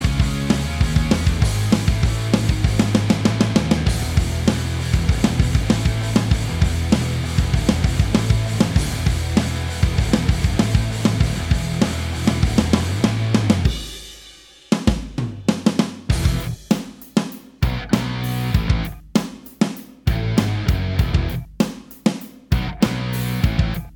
Minus Main Guitar Indie / Alternative 3:04 Buy £1.50